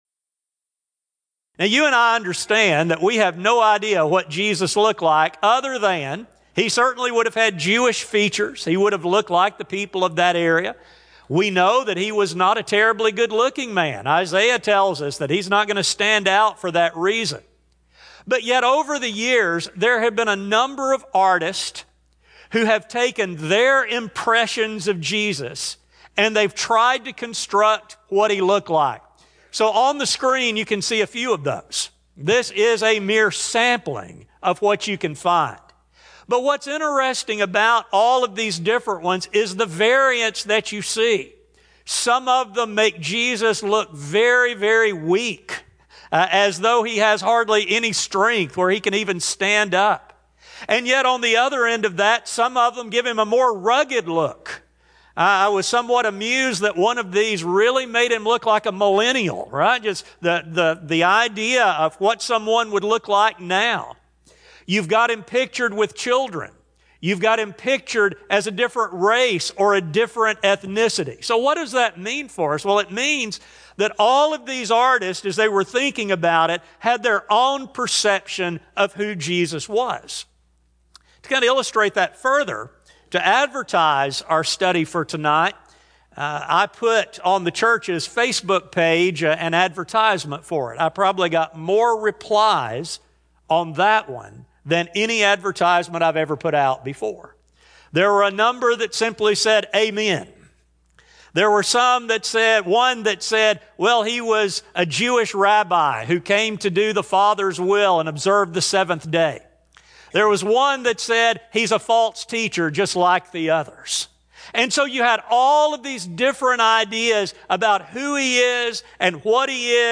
Service: Community Bible Study Type: Sermon